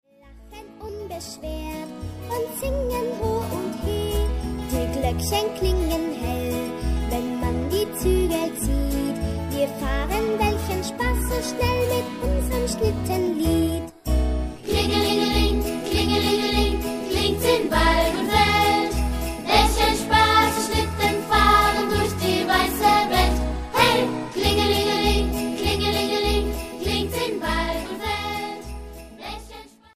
eingerichtet für drei Chorstimmen und Begleitung